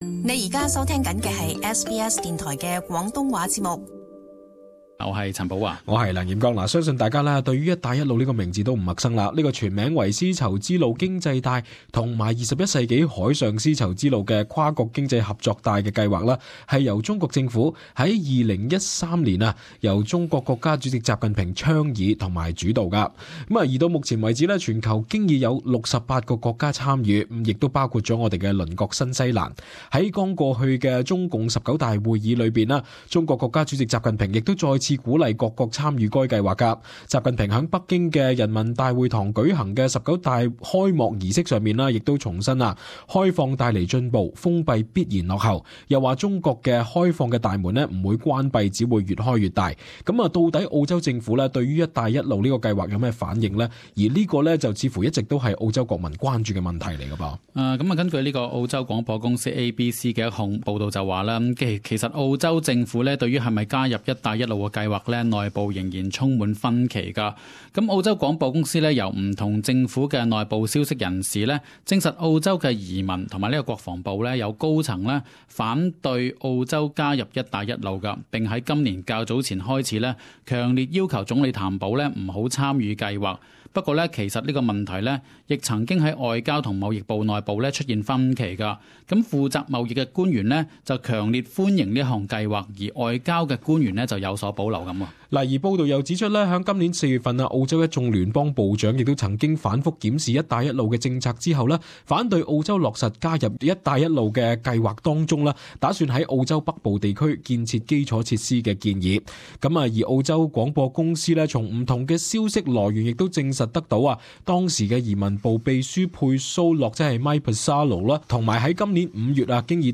【時事報導】澳洲官員對「一帶一路」分歧嚴重